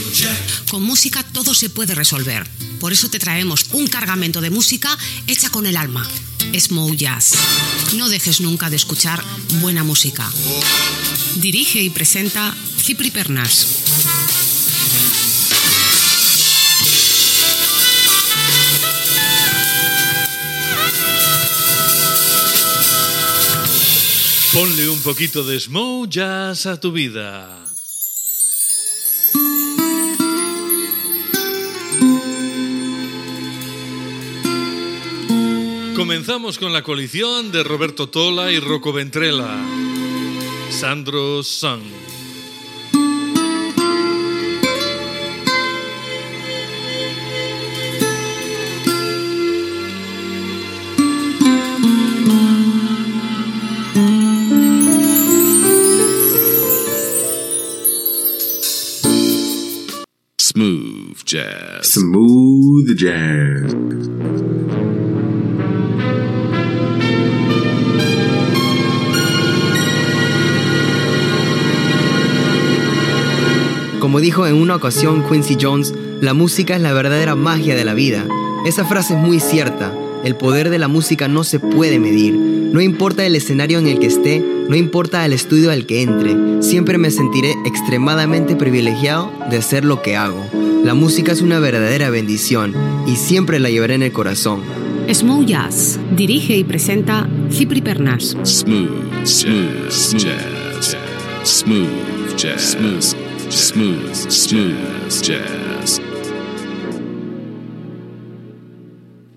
Careta del programa, presentació, tema musical i indicatiu
Musical